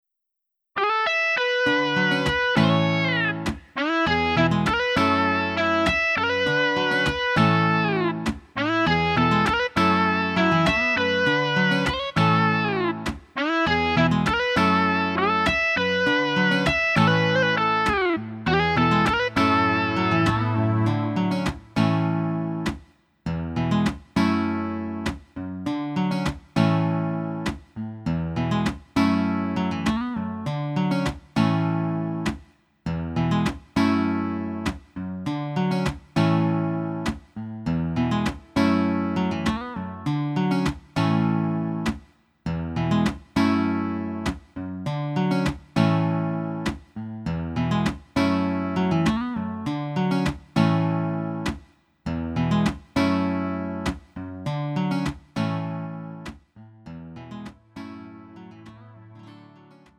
음정 -1키 2:49
장르 가요 구분 Lite MR